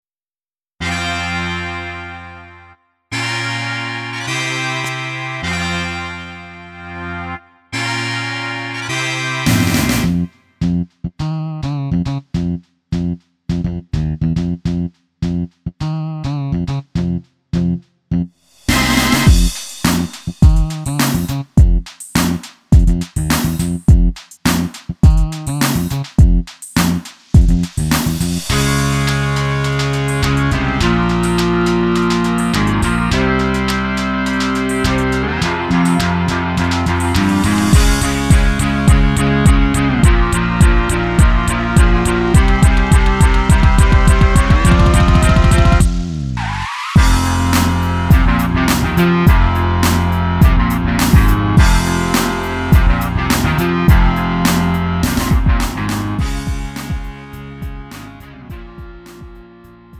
음정 -1키 장르 가요
Lite MR